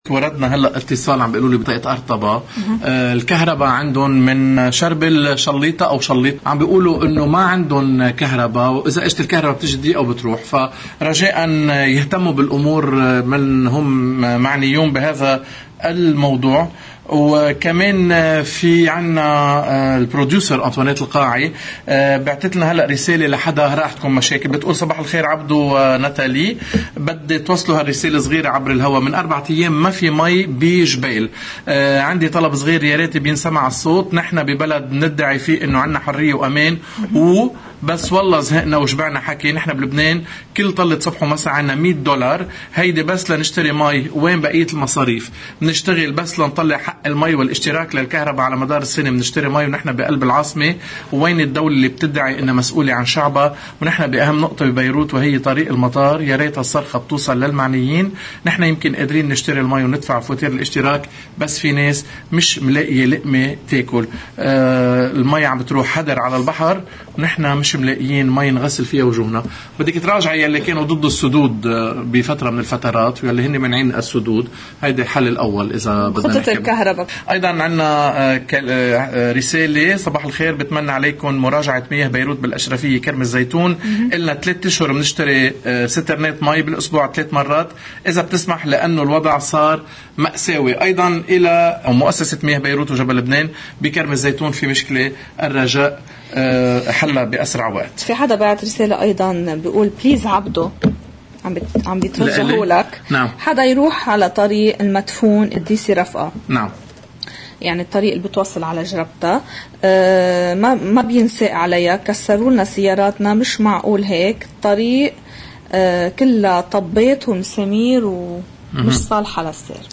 ضمن فقرة “اجندا اليوم” على قناة الـ”OTV” انهالت اتصالات المواطنين للمحطة لتشير الى أزمة المياه التي تطال قضاء جبيل وبيروت – طريق المطار والأشرفية كرم الزيتون. إضافة الى عدة مشاكل اخرى